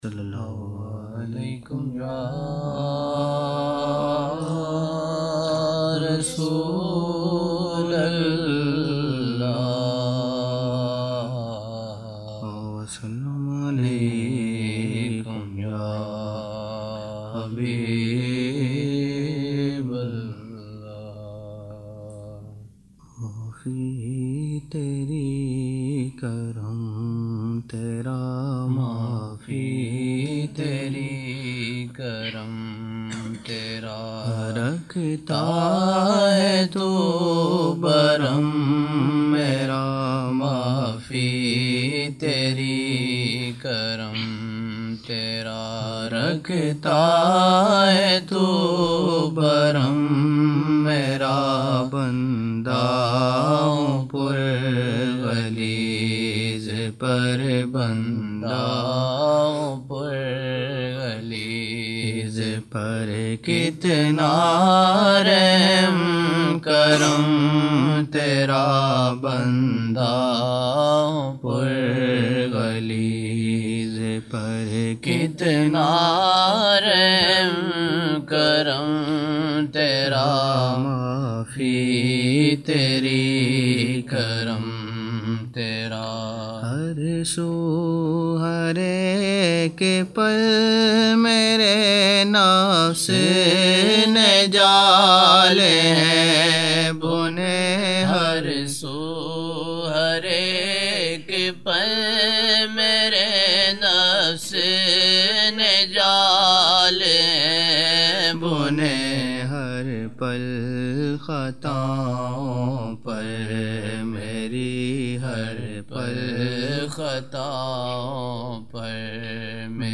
18 November 1999 - Maghrib mehfil (10 Shaban 1420)
Naat shareef